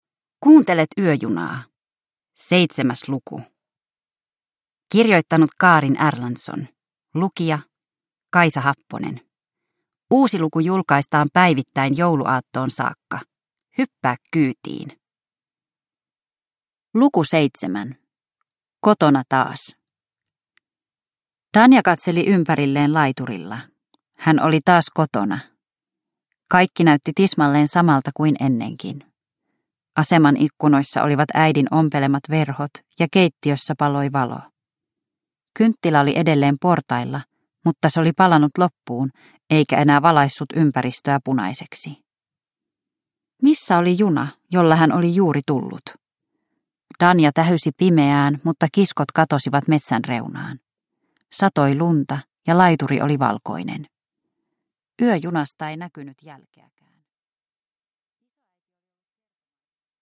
Yöjuna luku 7 – Ljudbok